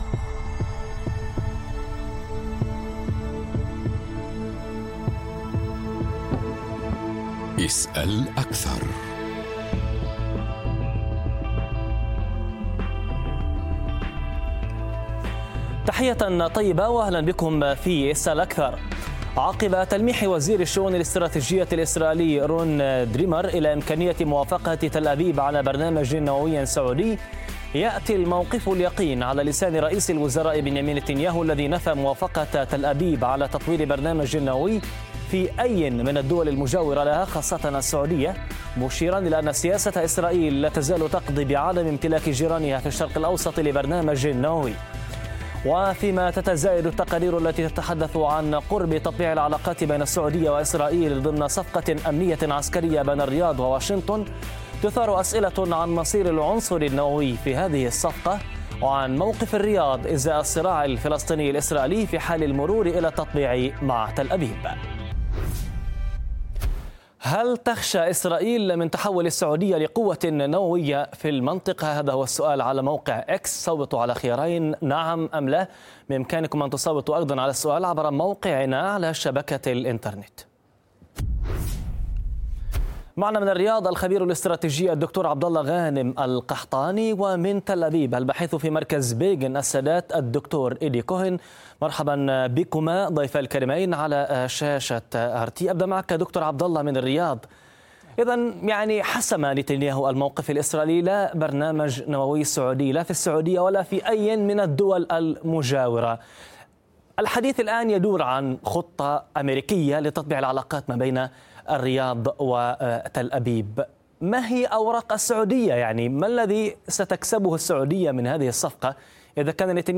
في تصريح لموقع قناة RT